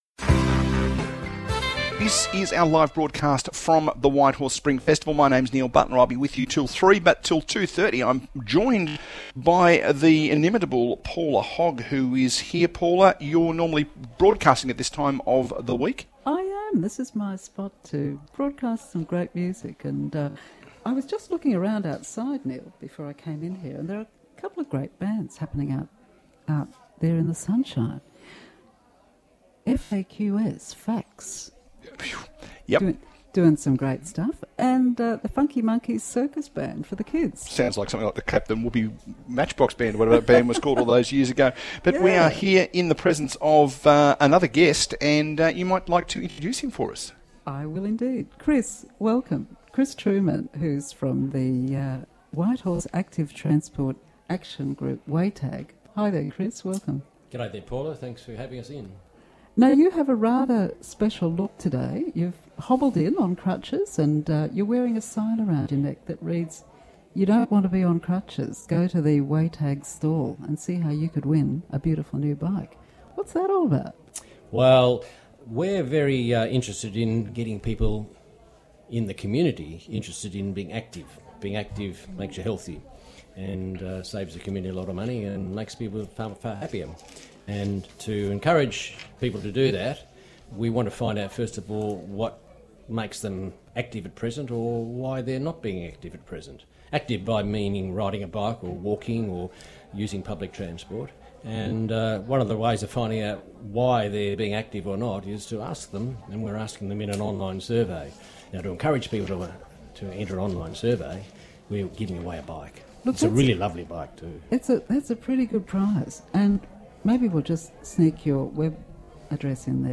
Whitehorse Spring Festival 2017
Local Radio 94.1FM - 3WBC conducted interviews with several Festival participants.